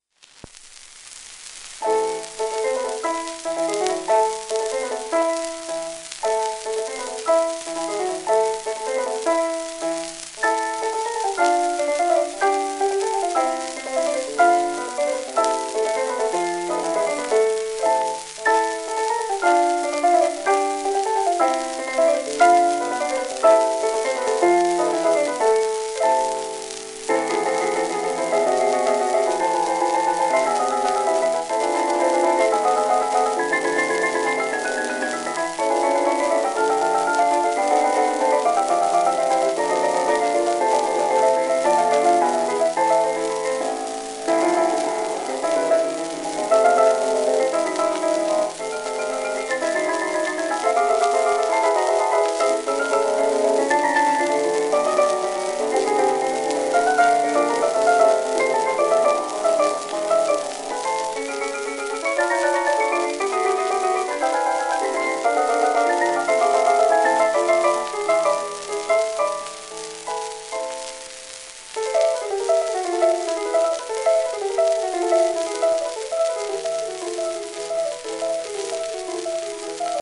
ラッパ吹込み時代の1924年頃の録音
旧 旧吹込みの略、電気録音以前の機械式録音盤（ラッパ吹込み）